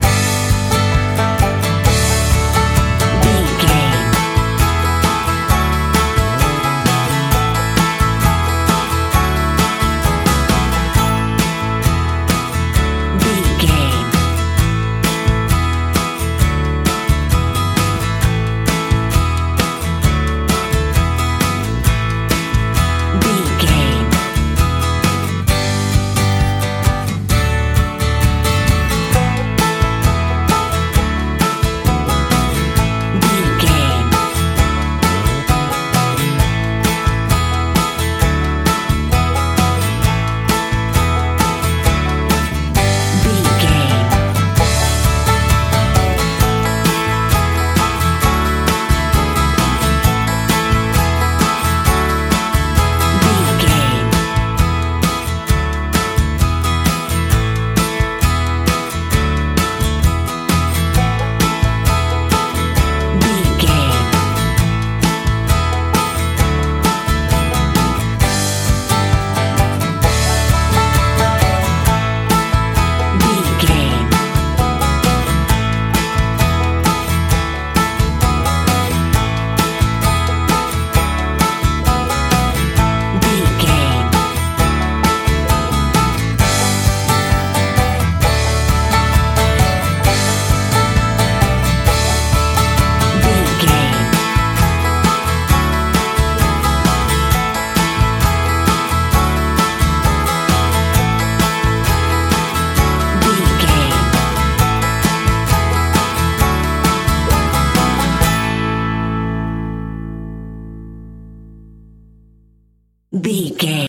Ionian/Major
Fast
drums
electric guitar
bass guitar
banjo
acoustic guitar
Pop Country
country rock
bluegrass
uplifting
driving
high energy